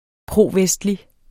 Udtale [ ˈpʁoˌvεsdli ]